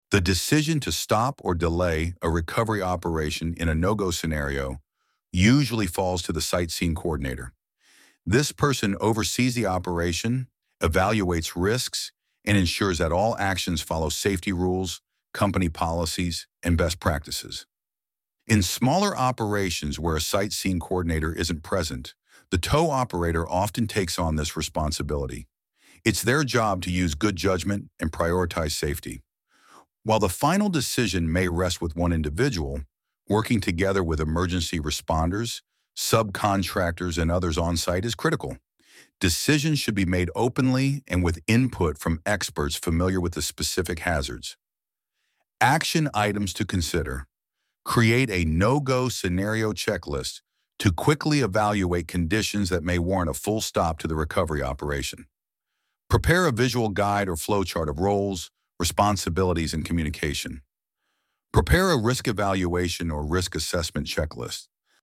ElevenLabs_Topic_1.6.3.3.mp3